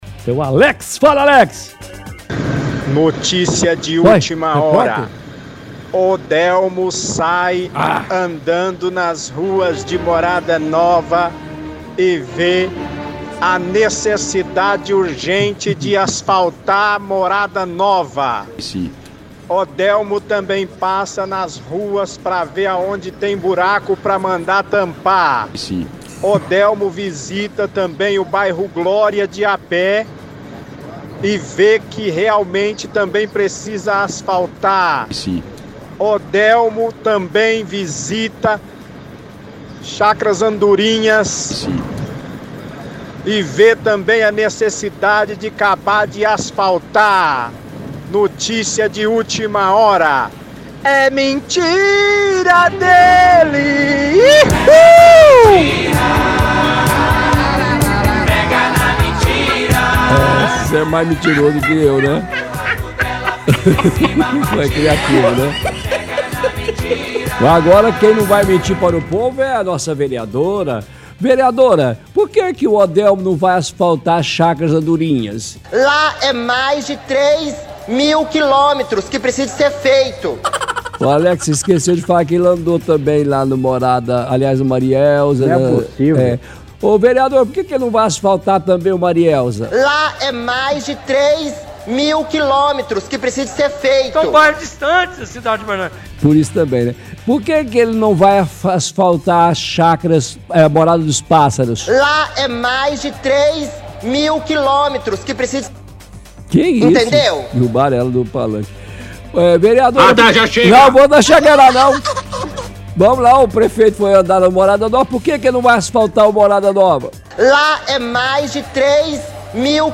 – Ouvinte envia áudio debochando, ironicamente, que Odelmo está andando pelas ruas de Uberlândia para ver os serviços que precisam ser feitos.